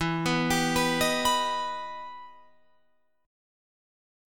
Listen to EmM7 strummed